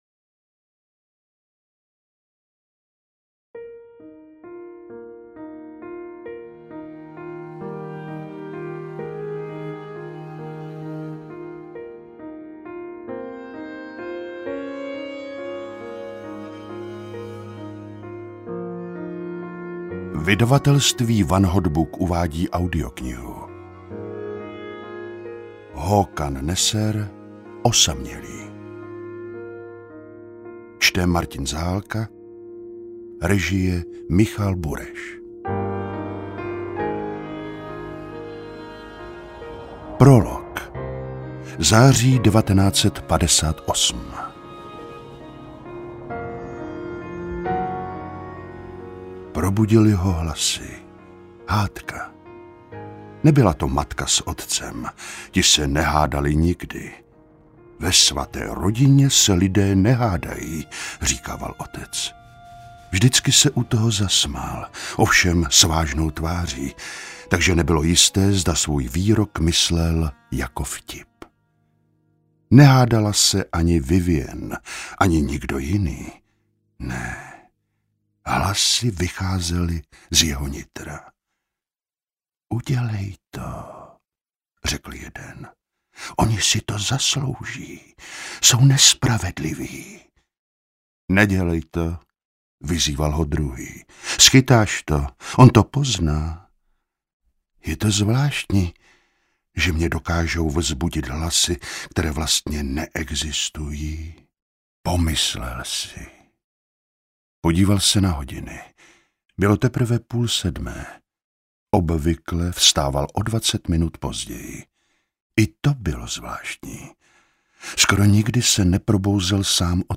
Interpret:  Martin Zahálka